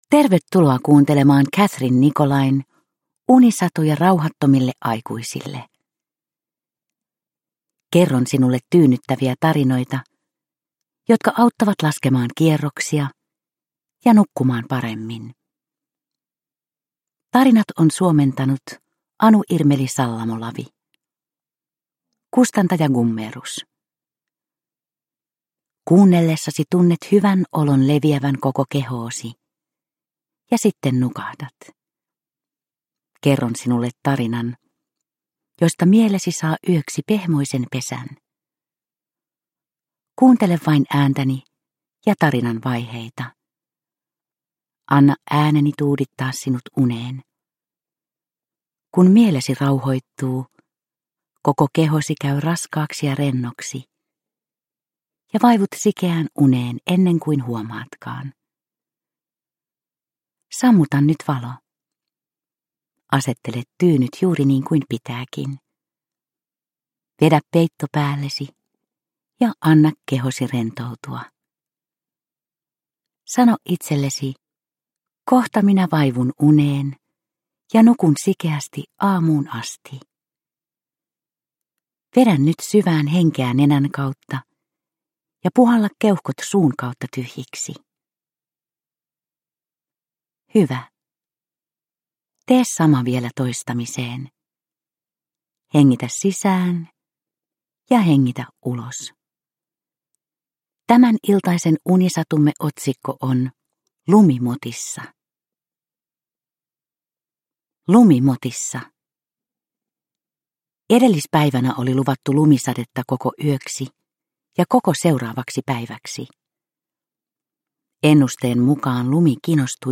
Unisatuja rauhattomille aikuisille 11 - Lumimotissa – Ljudbok – Laddas ner